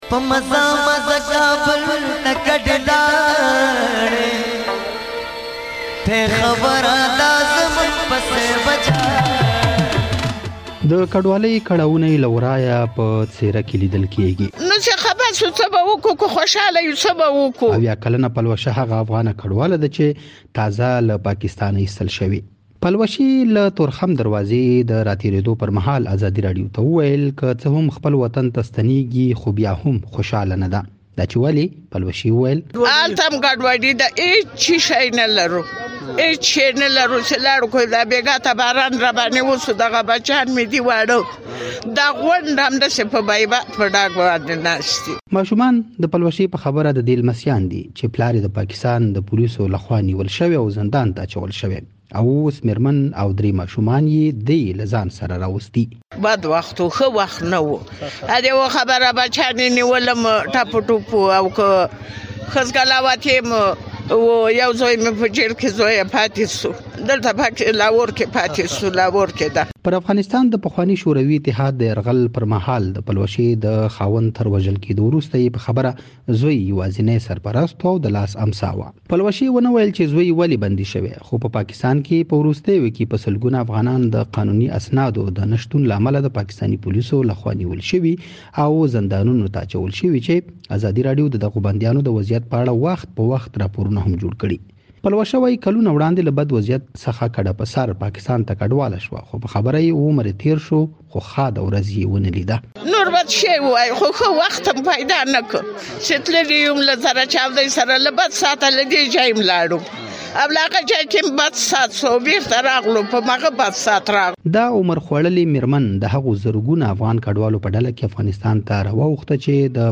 زموږ خبریال په تورخم کې دغې افغانې مېرمنې سره خبرې کړي او پر ژوند یې دا راپور جوړ کړی.